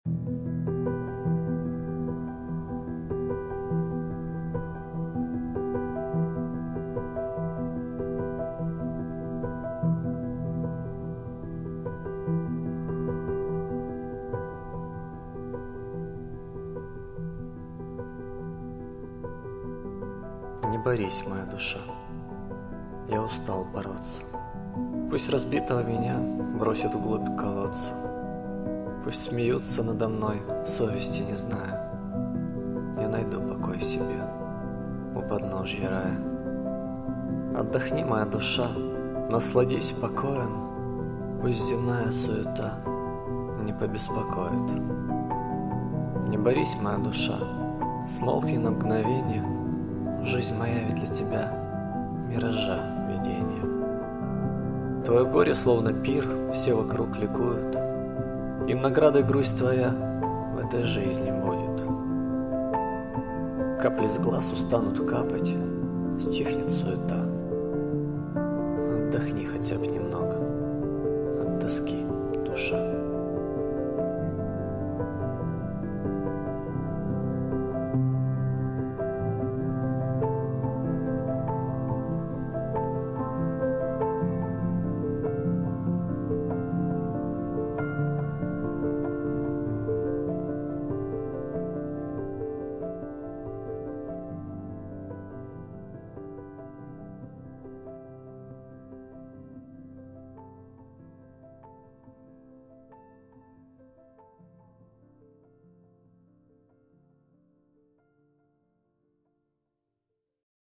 Не борись моя душа_с реверберацией.mp3